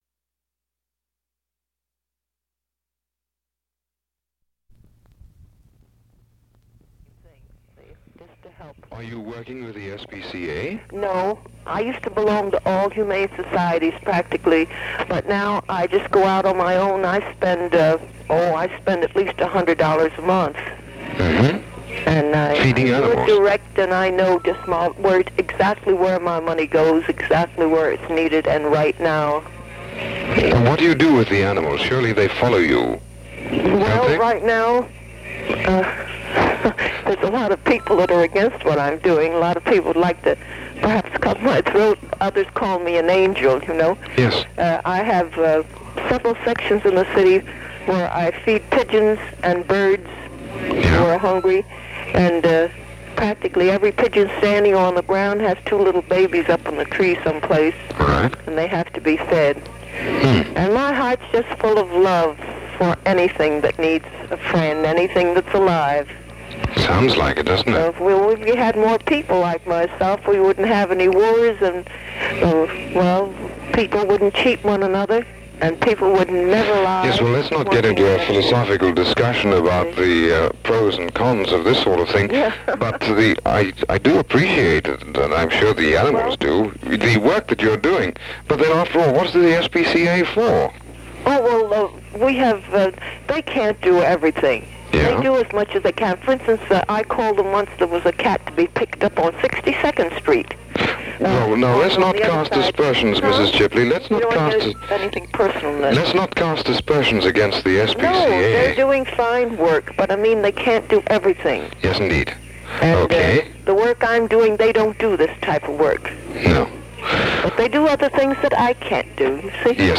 There were a few cool stations just getting started, but most of the stations on the FM dial nobody really thought about, including this one; a very short-lived and somewhat bizarre experiment called K-ADS. K-ADS was a format where you would call in with something you had to sell and pitch it on the air.